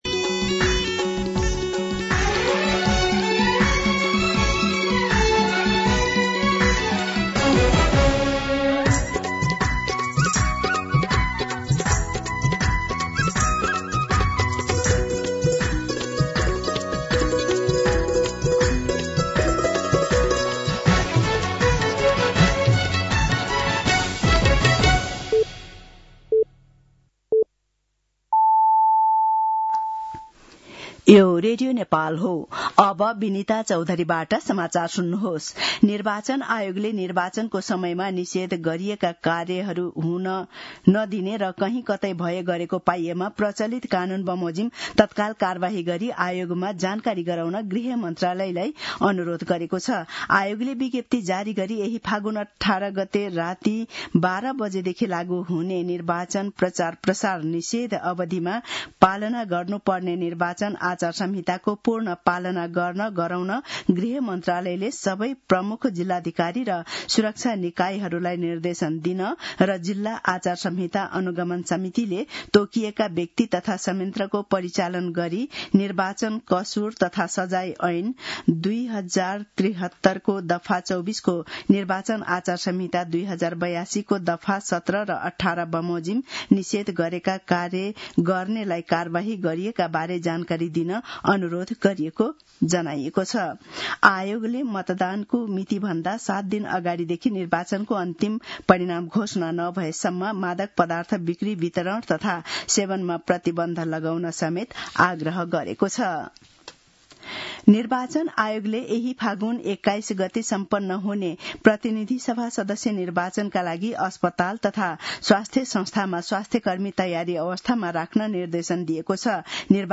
दिउँसो १ बजेको नेपाली समाचार : १४ फागुन , २०८२